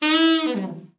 ALT FALL  15.wav